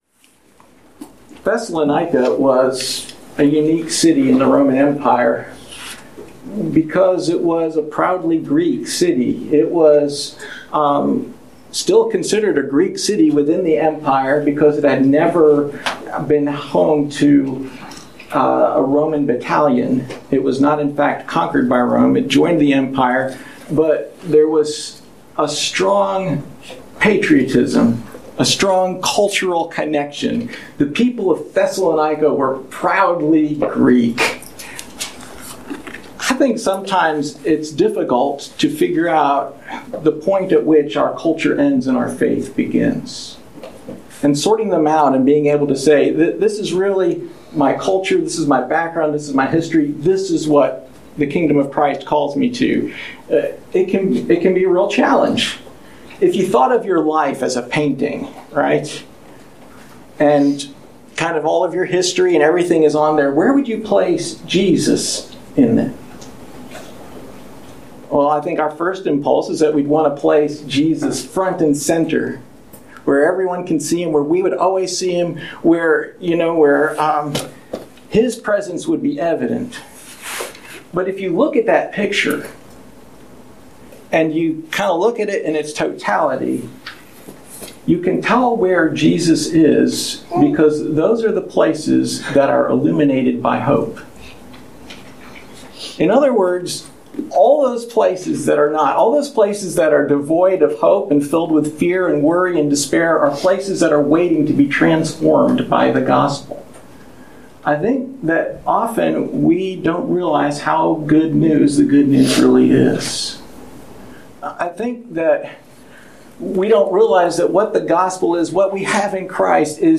Passage: 1 Thessalonians 1:1-10 Service Type: Sunday Morning